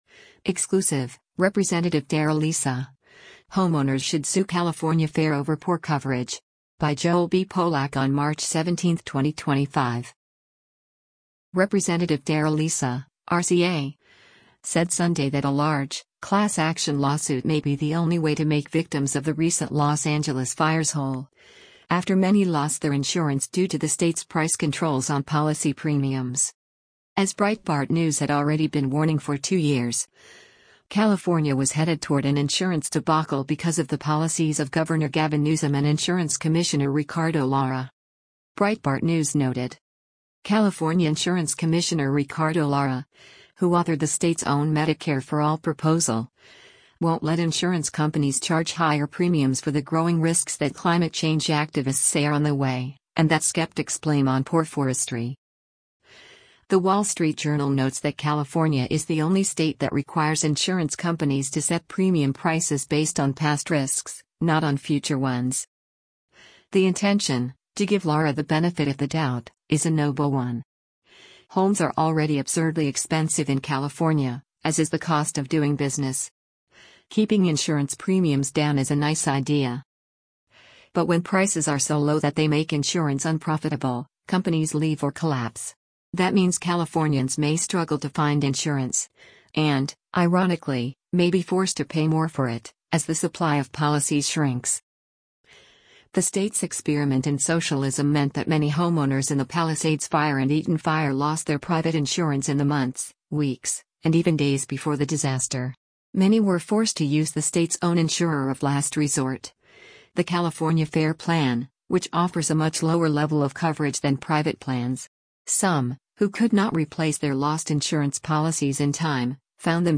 Issa, speaking to Breitbart News Sunday on SiriusXM Patriot 125, said that one solution to the insurance crisis, going forward, would be to pass federal legislation to allow insurance to be sold across state lines.